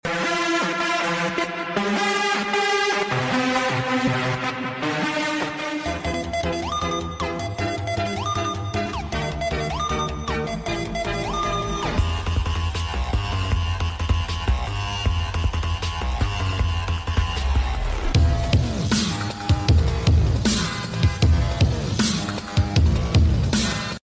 MM2 Victory Sounds! 🔥🔥 ;D sound effects free download